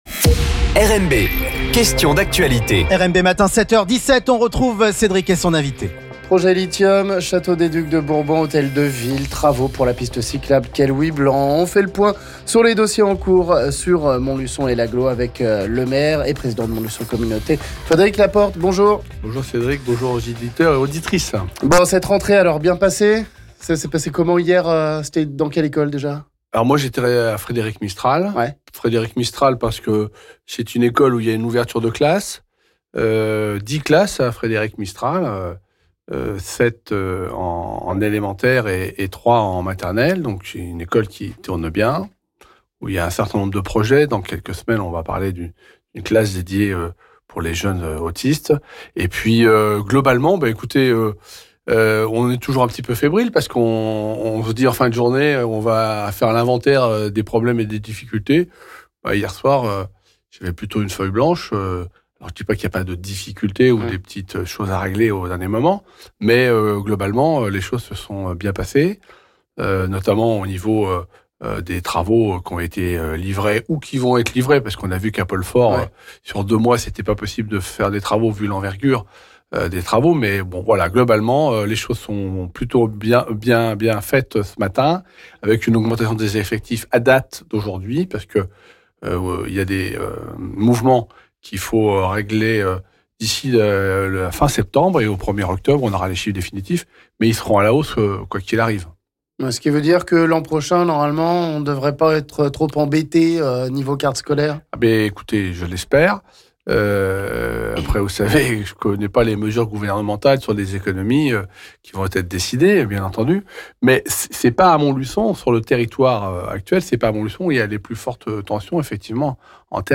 Le maire de Montlu�on Fr�d�ric Laporte nous parle des sujets d'actualit� entre lithium et projets sur la ville - L'Invit� du Jour